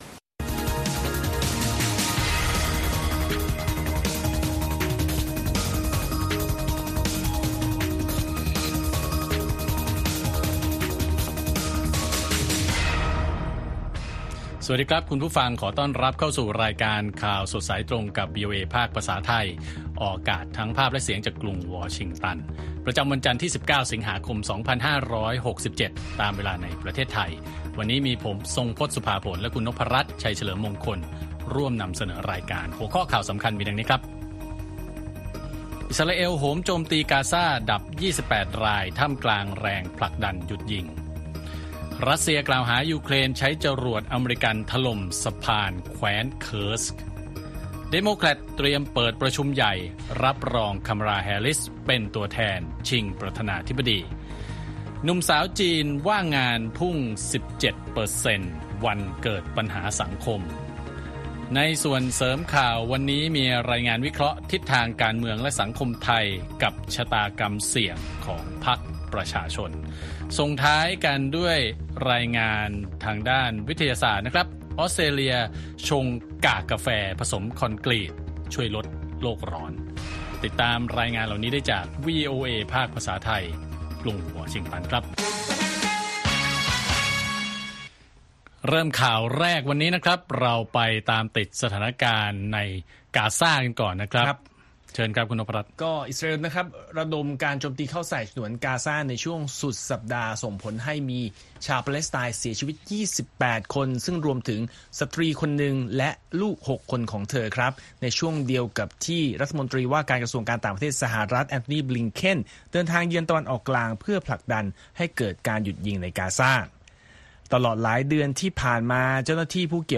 ข่าวสดสายตรงจากวีโอเอ ไทย ประจำวันที่ 19 สิงหาคม 2567